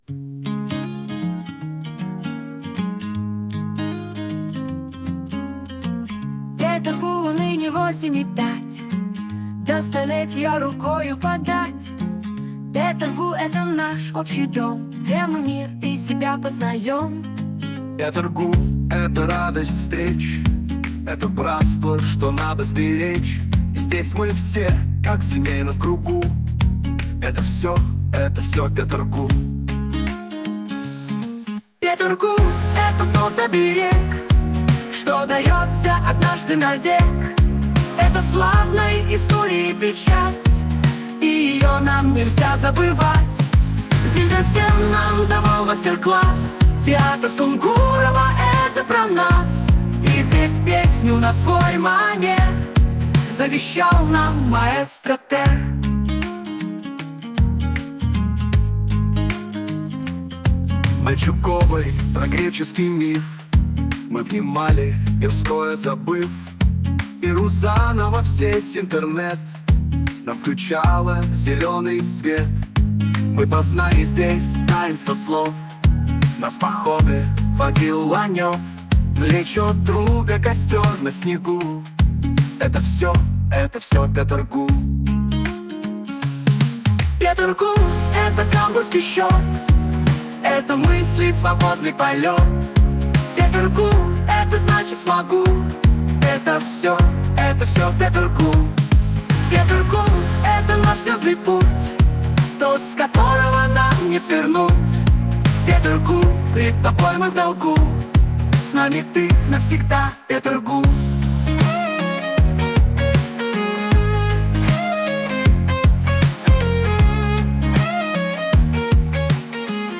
а музыку придумал искусственный интеллект.